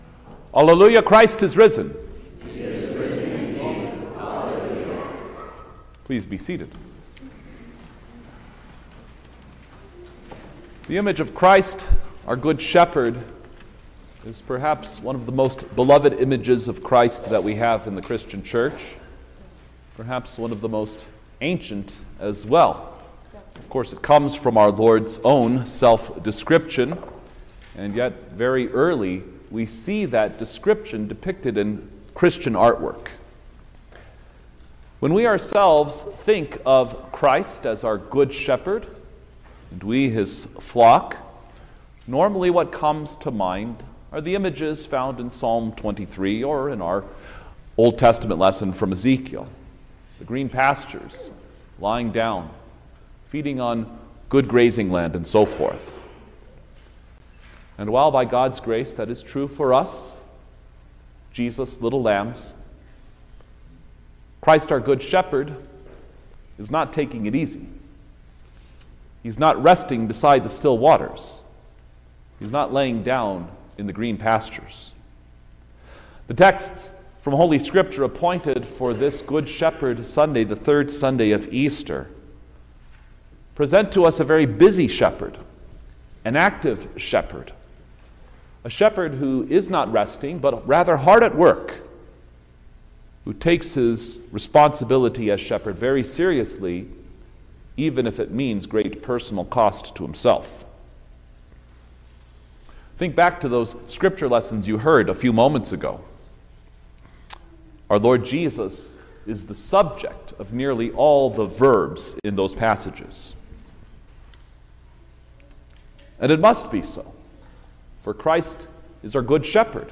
May-4_2025_Third-Sunday-of-Easter_Sermon-Stereo.mp3